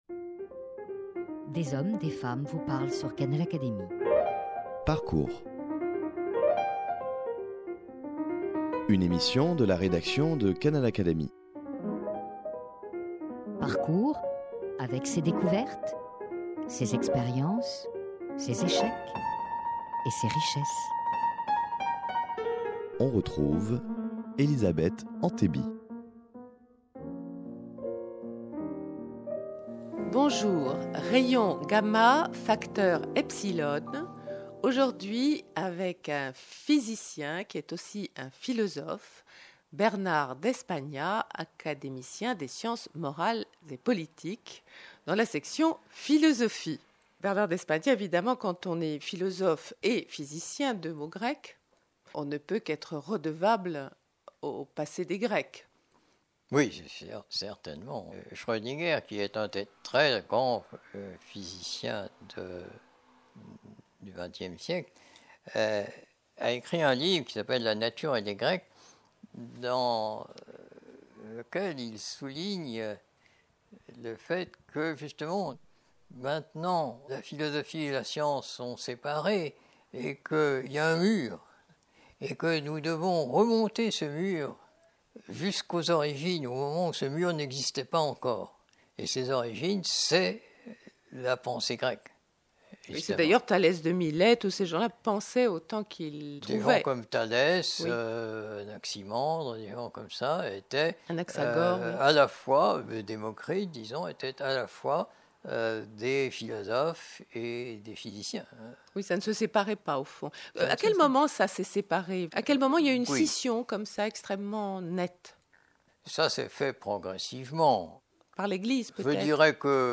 Écoutez Bernard d'Espagnat qui nous reçoit à son domicile pour répondre à nos questions.